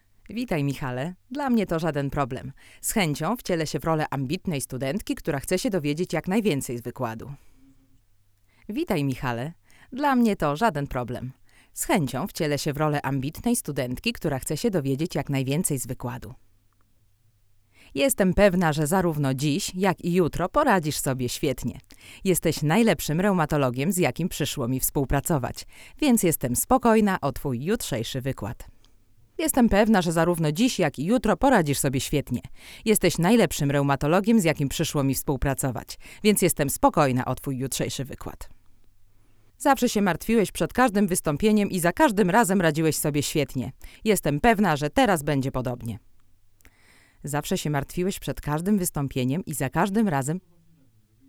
Do testów używamy popularnego Neumanna TLM 103, i dla równowagi U89 tego samego producenta.
Brzmienie tego przedwzmacniacza naprawdę nie pozostawia wiele do życzenia.